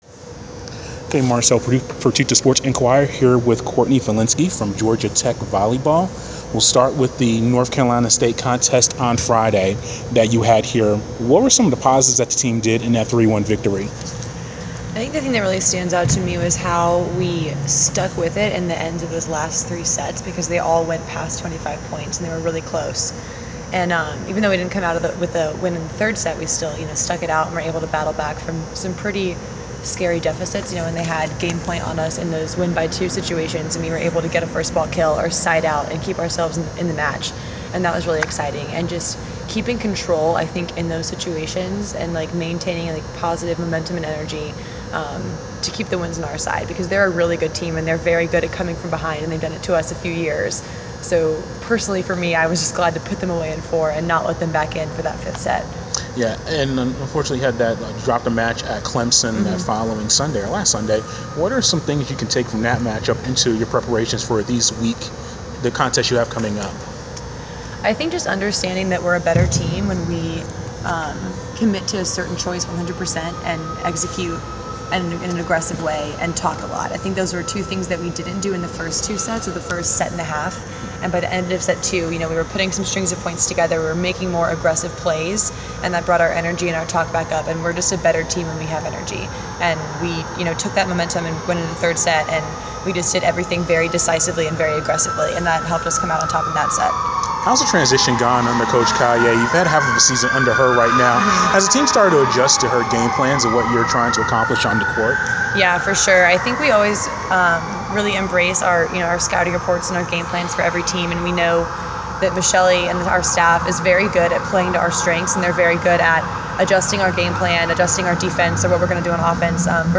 Inside Georgia Tech: Interview